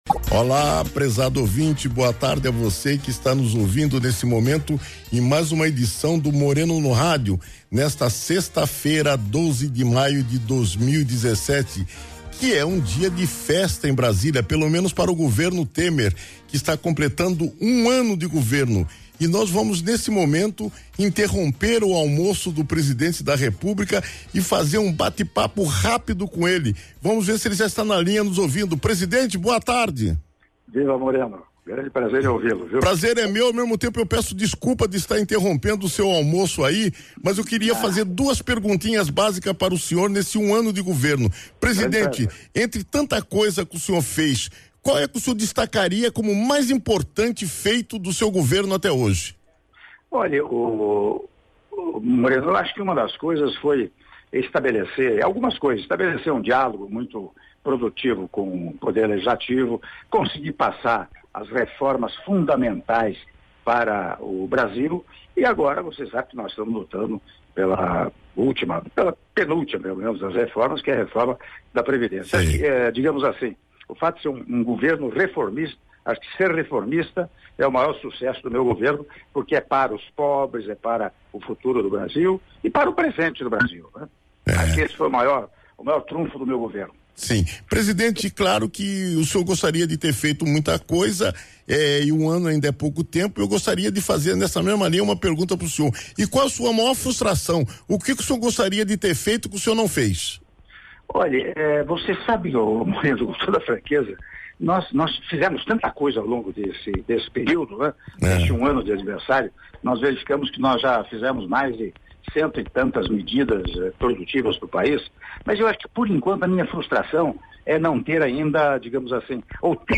Áudio da entrevista exclusiva do Presidente da República, Michel Temer, à Rádio CBN - (02min40s) - Brasília/DF